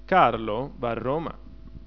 The following, for example, is a possible patterned declarative sentence in Italian: